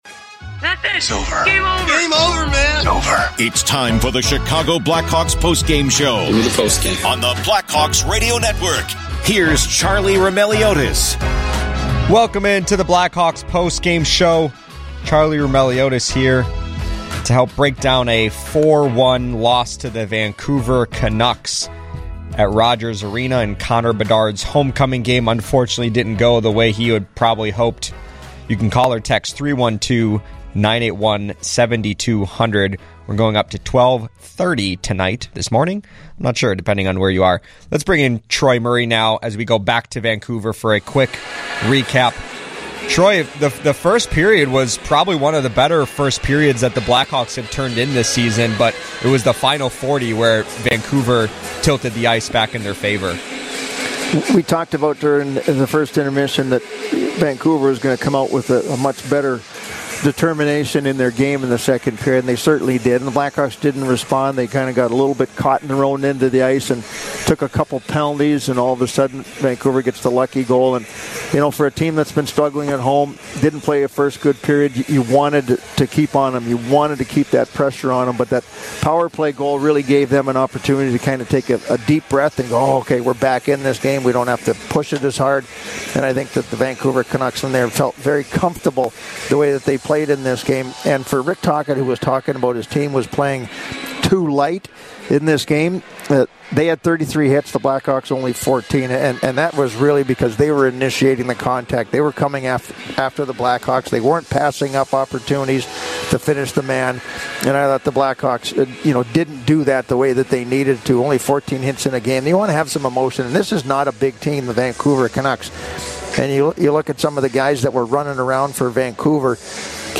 Troy Murray joins the discussion from Rogers Arena to discuss Connor Bedard’s quiet homecoming game, how the Blackhawks can break out of their offensive slump, and more.
Later in the show, Alex Vlasic, Ilya Mikheyev, and head coach Luke Richardson share their thoughts on the loss.